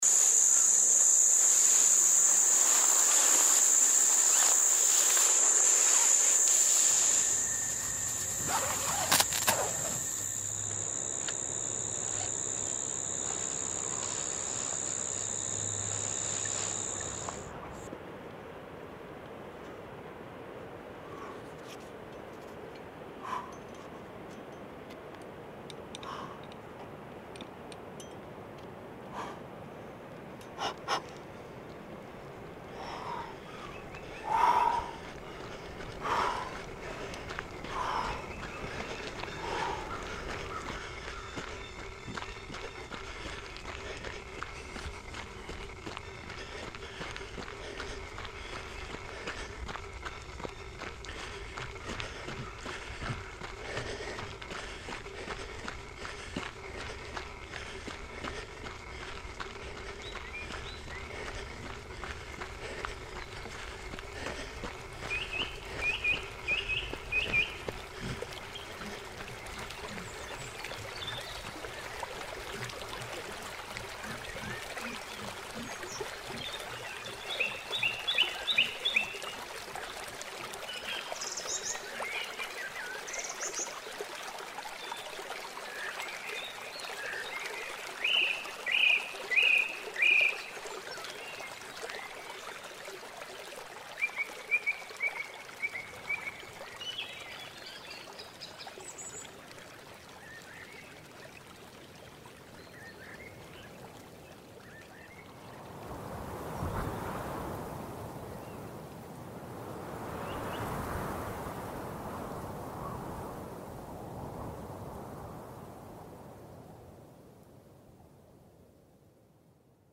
“Mountain” T-Shirt captures the energy of a real mountain run, layered with the sounds of boots striking uneven earth, wind threading through alpine air, distant waterfalls echoing in the valley, and the occasional call of mountain birds. Somewhere in the mix, you’ll hear the quiet ritual of a tent being mounted, a moment of pause amid motion.
A tribute to the thin, clear silence of elevation and the fleeting sounds that shape the experience of being above it all.
montanha_mixagem.mp3